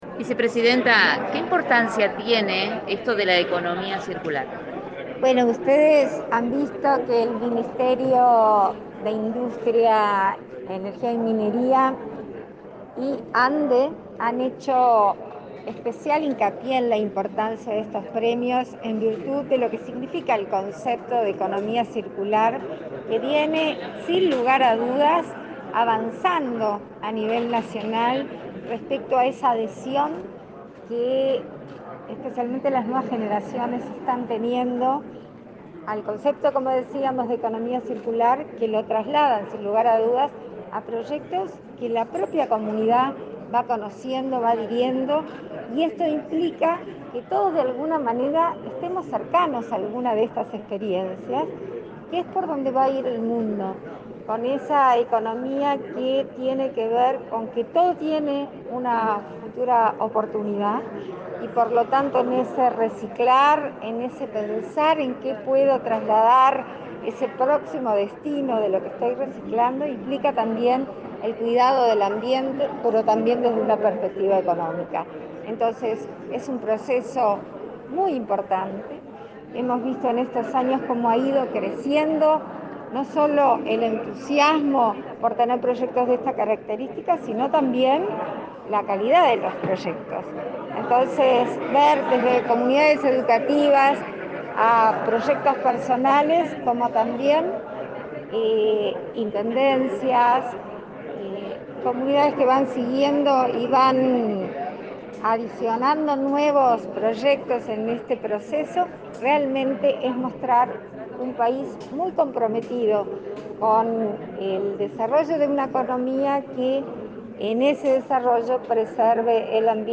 Entrevista a la vicepresidenta Beatriz Argimón
La vicepresidenta de la República, Beatriz Argimón, participó, este miércoles 27 en Montevideo, en la entrega del premio Uruguay Circular y, luego,